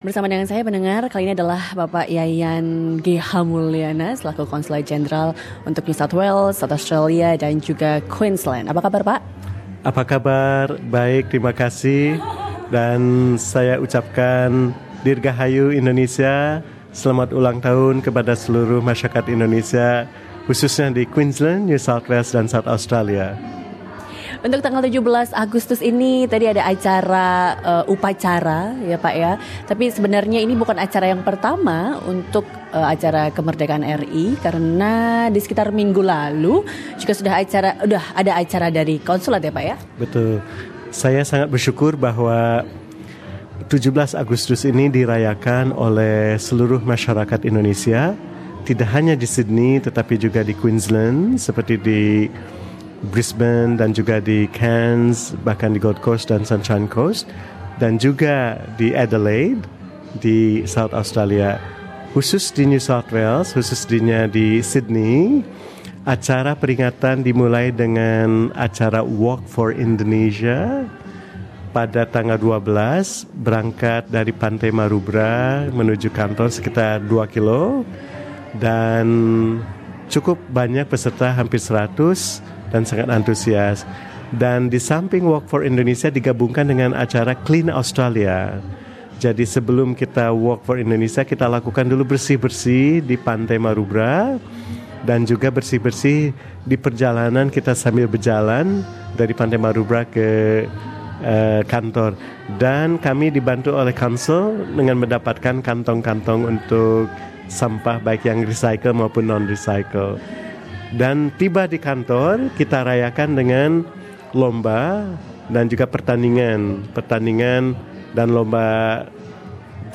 Peringatan hari ulang tahun Republik Indonesia ke-72 dilangsungkan kemarin di Wisma Indonesia, Rose Bay. SBS Radio berbincang dengan Konsulat Jenderal Indonesia untuk NSW, Queensland, dan South Australia, Bapak Yayan G. H. Mulyana tentang pelaksanaan rangkaian peringatan hari Kemerdekaan RI dan komunitas Indonesia di Australia.